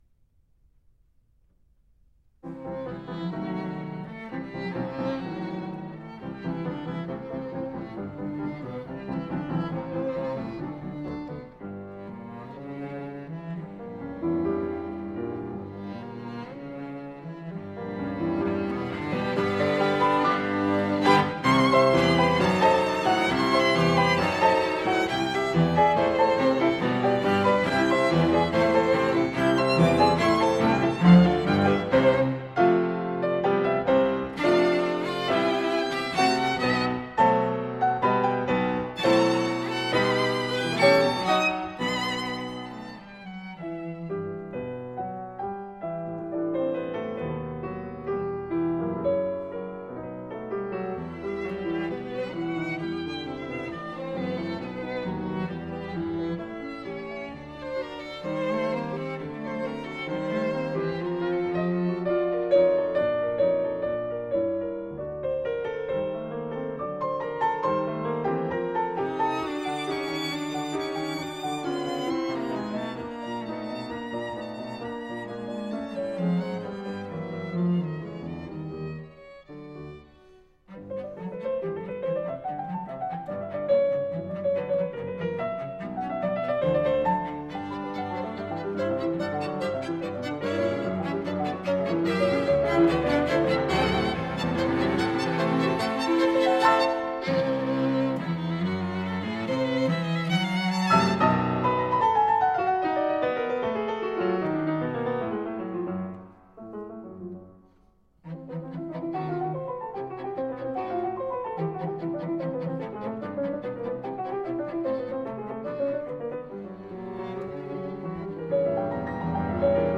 A group of three musicians.
Trio for Piano, Violin and violoncello No 2 in C Major
Finale Scherzo-Presto